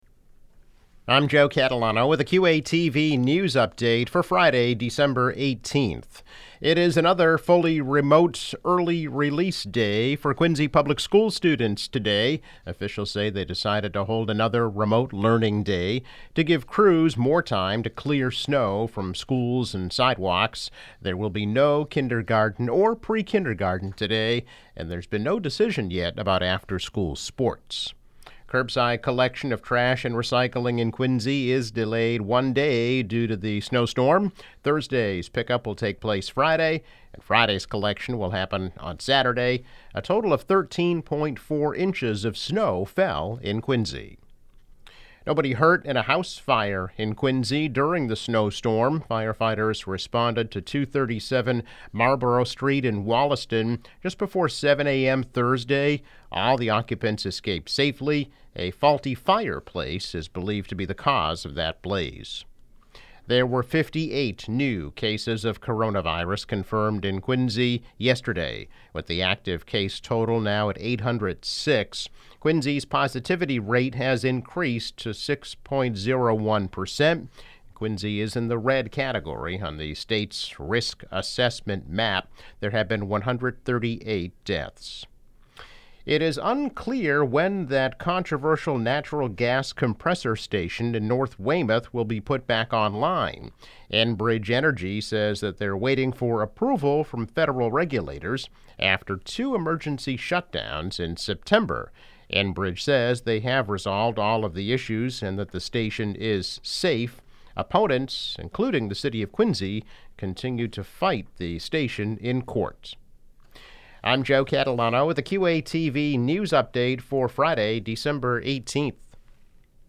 News Update - December 18, 2020